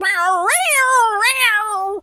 pgs/Assets/Audio/Animal_Impersonations/cat_scream_10.wav at 7452e70b8c5ad2f7daae623e1a952eb18c9caab4
cat_scream_10.wav